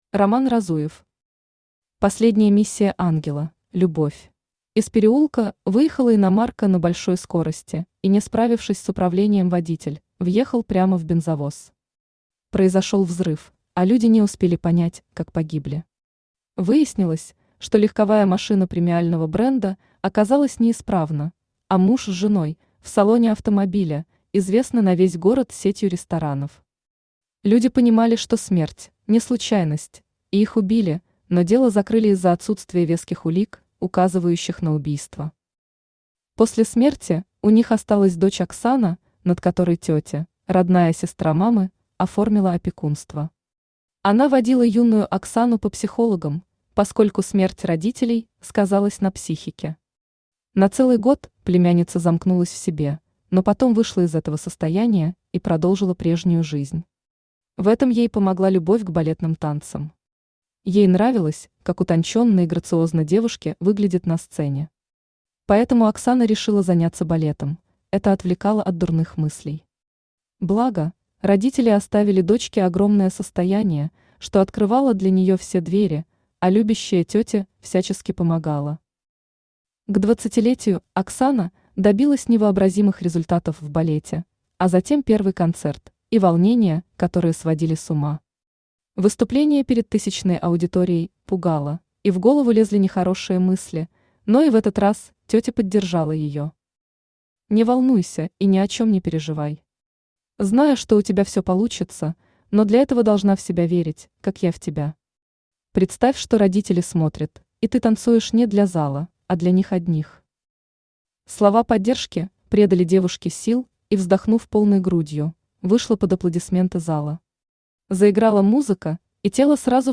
Аудиокнига Последняя миссия ангела: Любовь!
Автор RoMan Разуев Читает аудиокнигу Авточтец ЛитРес.